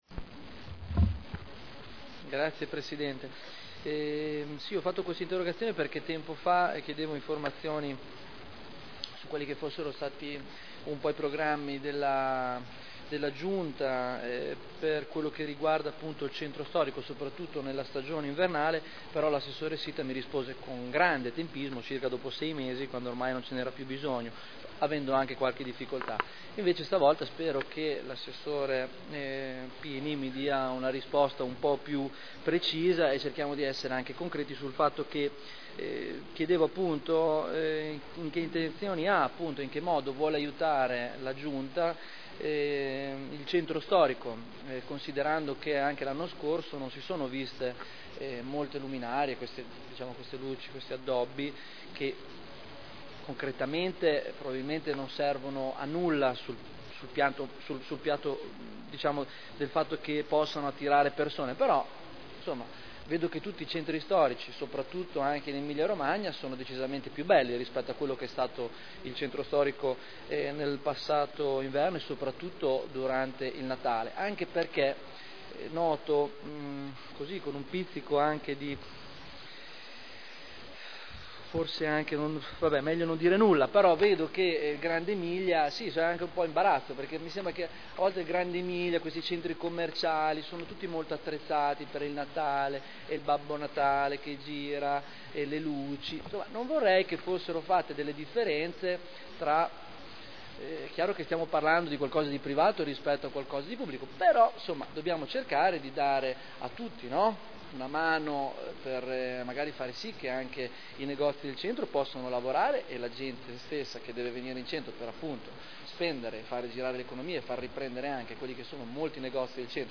Interrogazione del consigliere Barberini (Lega Nord) avente per oggetto: “Luminarie”
Audio Consiglio Comunale